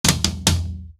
TOM     4C.wav